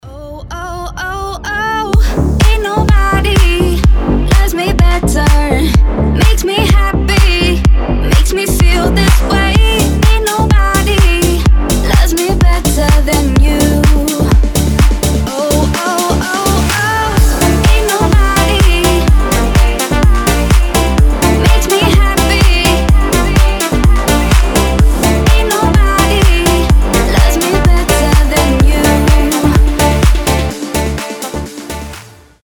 Dance Pop
приятный женский голос